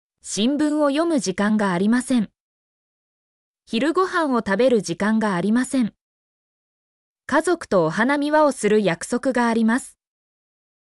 mp3-output-ttsfreedotcom-34_vlqVSTM9.mp3